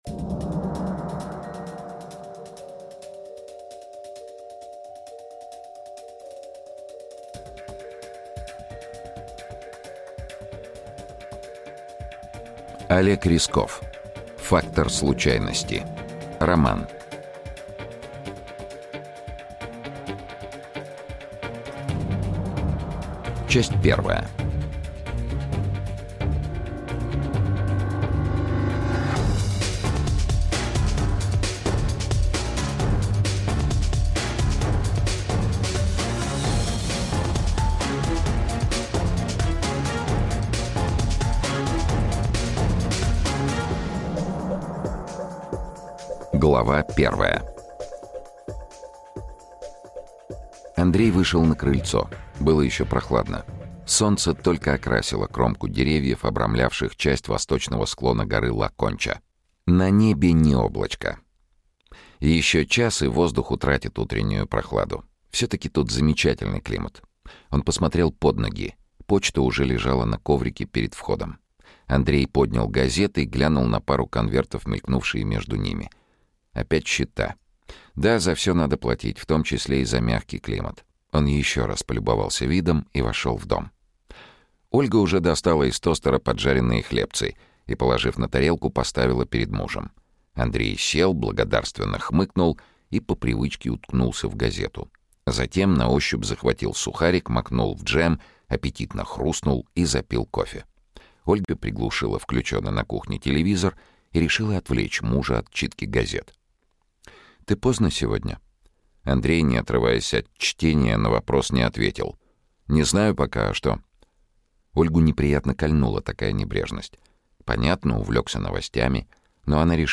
Aудиокнига Фактор случайности. 1 серия Автор Олег Рясков Читает аудиокнигу Сергей Чонишвили. Прослушать и бесплатно скачать фрагмент аудиокниги